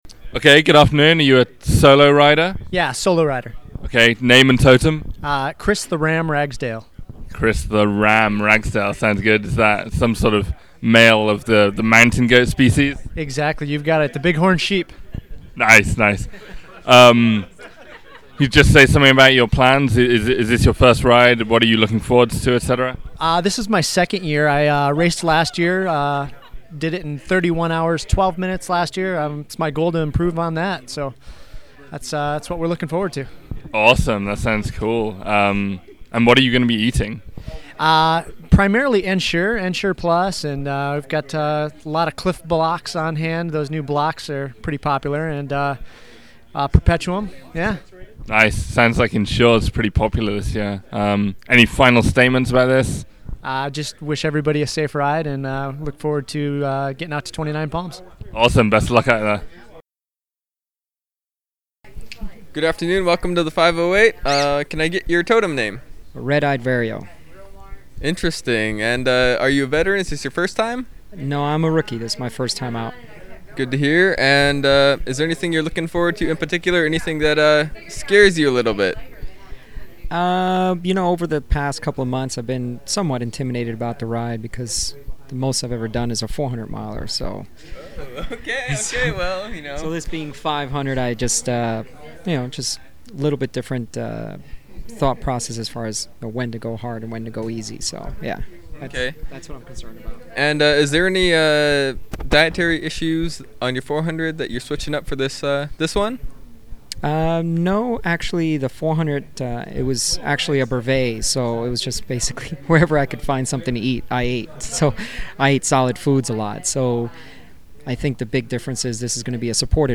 Interviews from mile 200